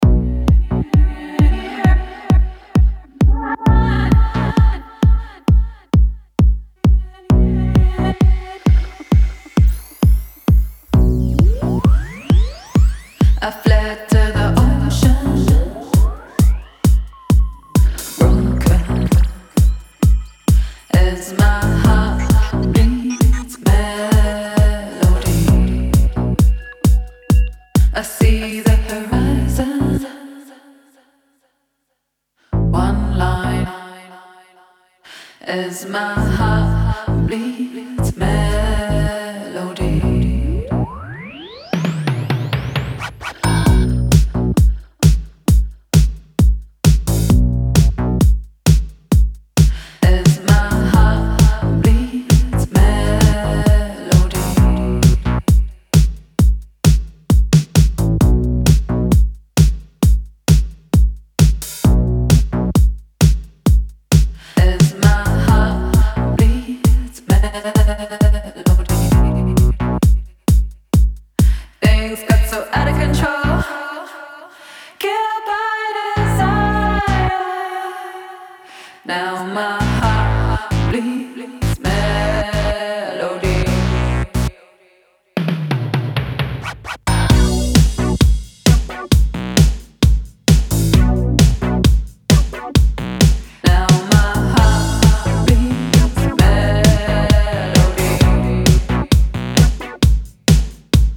Electro House Wave